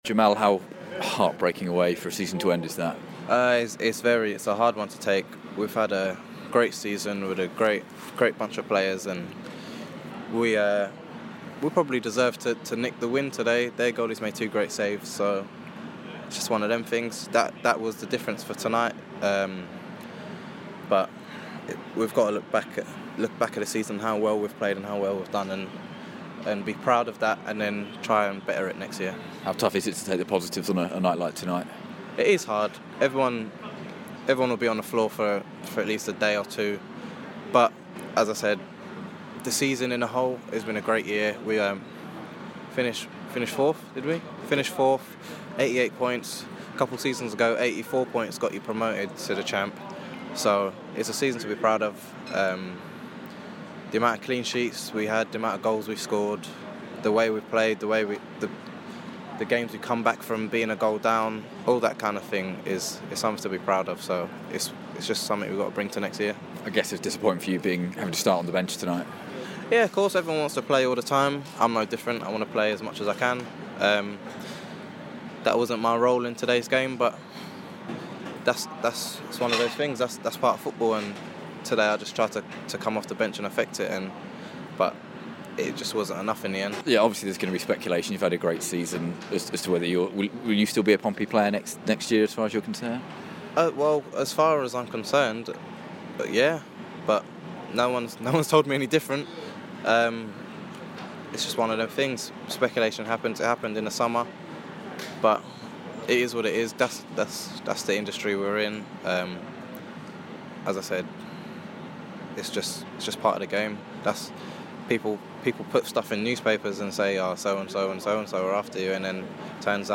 Jamal Lowe speaks after the 0-0 draw at home to Sunderland